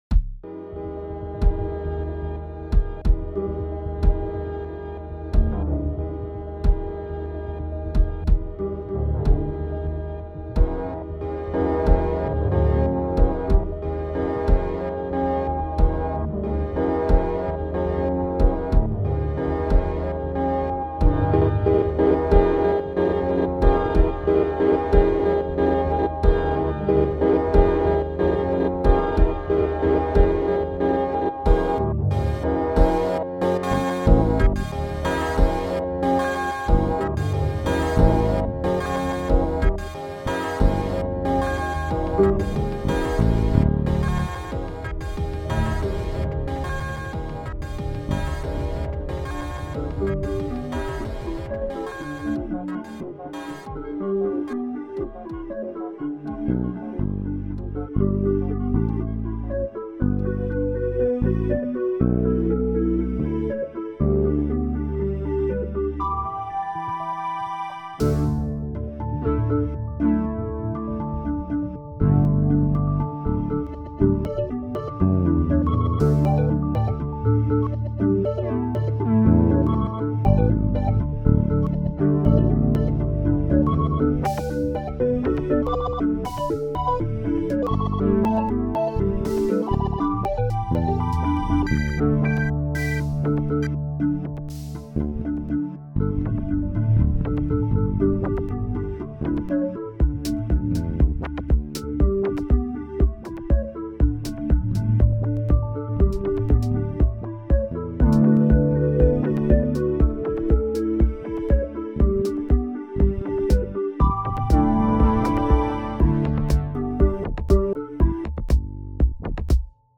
111 BPM
Excellent, creative & feelgood remix.